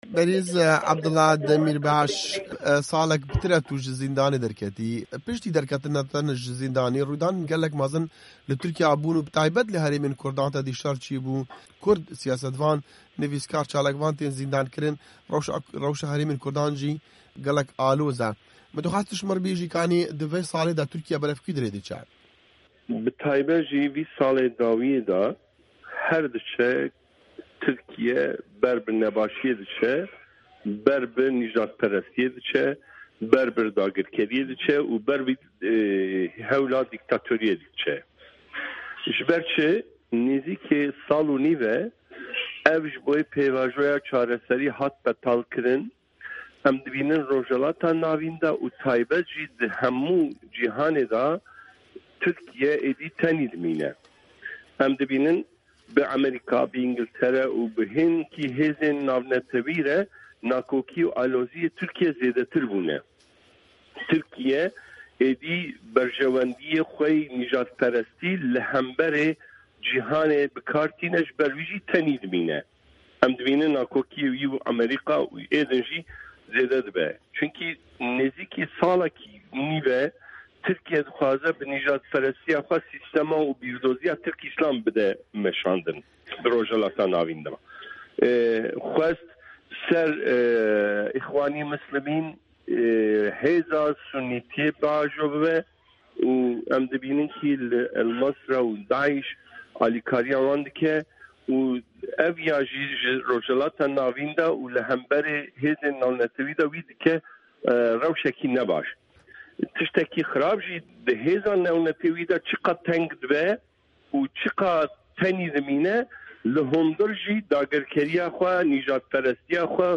Hevpeyvîn digel Abdullah Demîrbaş